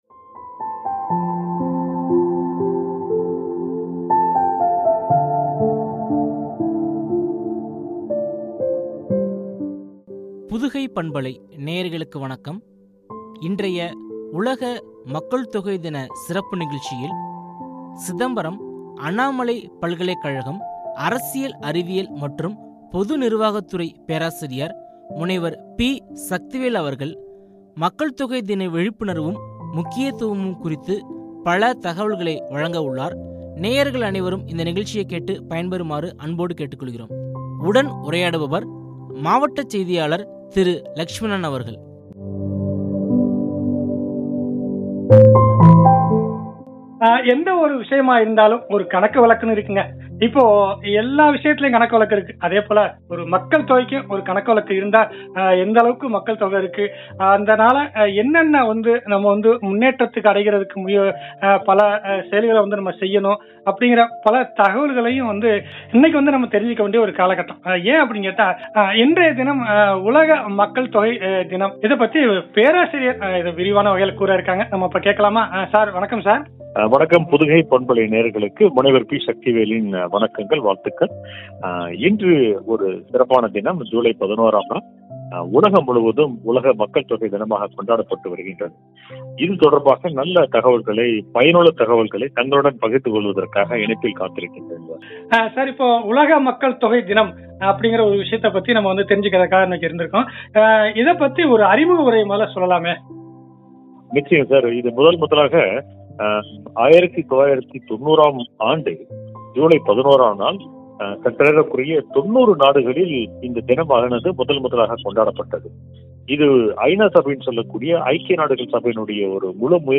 முக்கியத்துவமும்” என்ற தலைப்பில் வழங்கிய உரையாடல்.